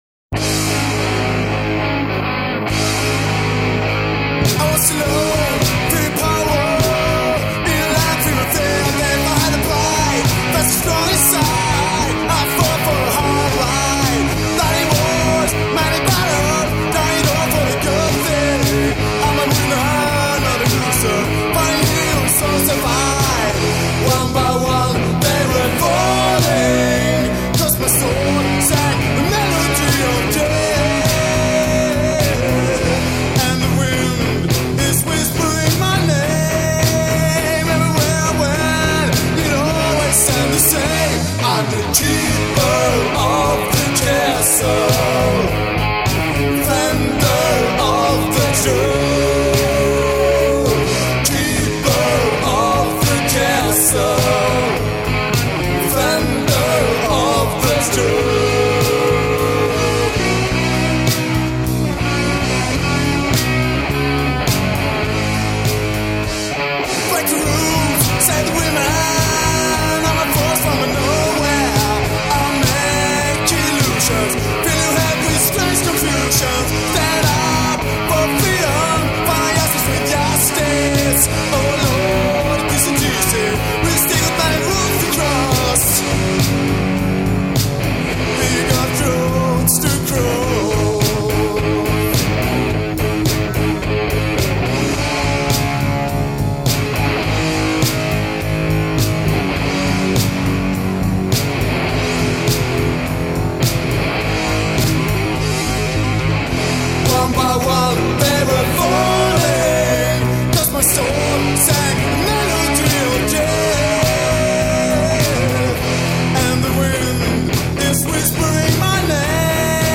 Bas 19år
Gitarr, sång 19år
Trummor 19år
Deras musik är oftas ösig men ändå polerad metall
Låtarna är från olika demoinepelningar.